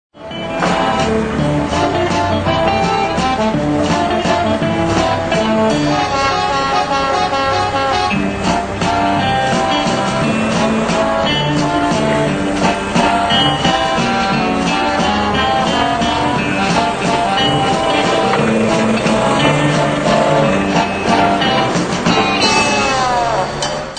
Ici, vous pouvez télécharger 4 extraits de la bande son de notre spectacle : si vous avez besoin d'un lecteur cliquez sur RealPlayer
valse désaccordée valse désaccordée